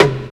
108 TOM HI.wav